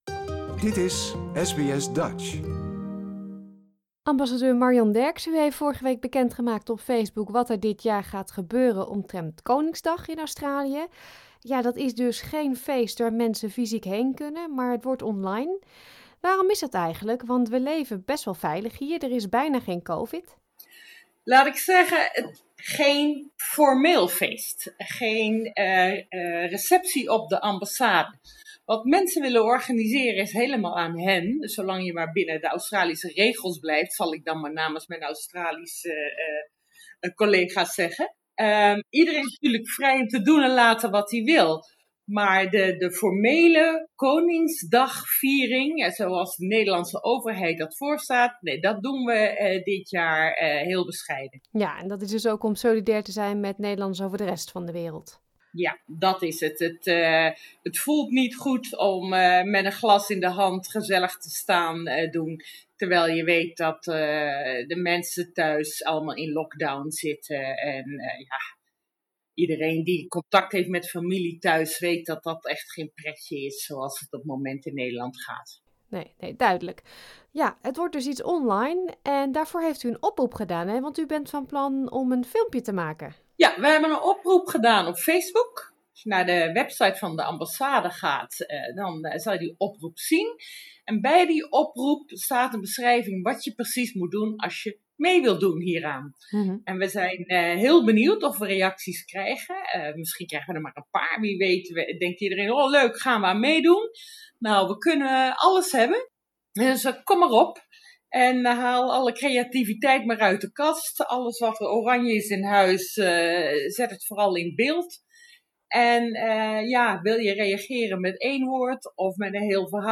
Enkele Australische landmarks zullen dit jaar oranje kleuren en ook roept ze Nederlanders Down Under op om een filmpje in te sturen. Ambassadeur Marion Derckx vertelt over de plannen.